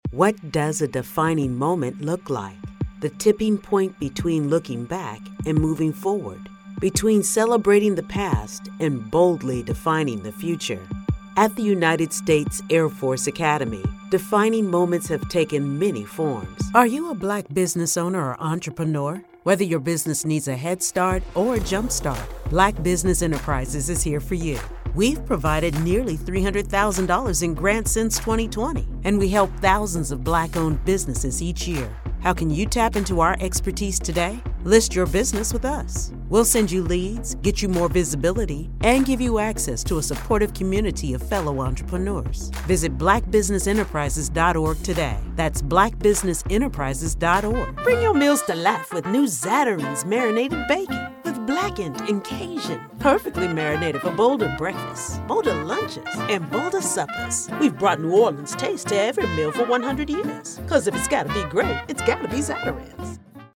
Female Voice Over, Dan Wachs Talent Agency.
Mature, Intelligent, Expressive
Showcase